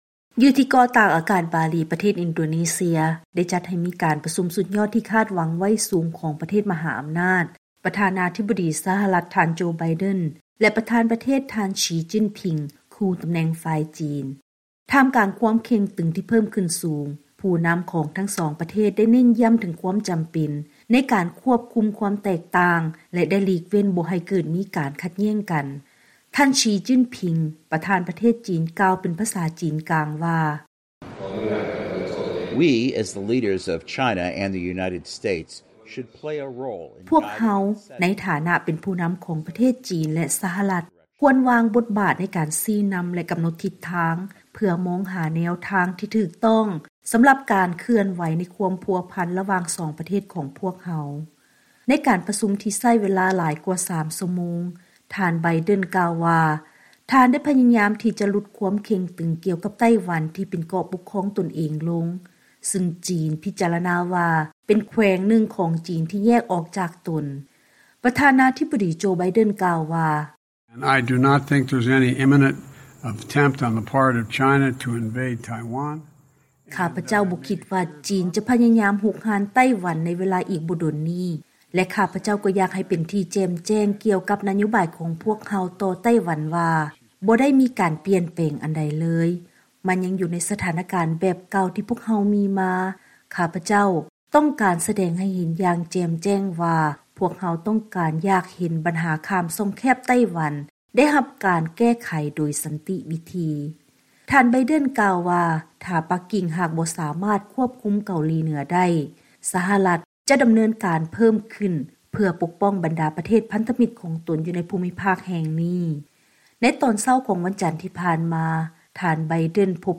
ເຊີນຮັບຟັງລາຍງານກ່ຽວກັບ ການພົບປະກັນຢູ່ນອກກອງປະຊຸມສຸດຍອດກຸ່ມ G-20 ຂອງປະທານາທິບໍດີໂຈ ໄບເດັນ ແລະປະທານປະເທດສີ ຈິ້ນຜິງ.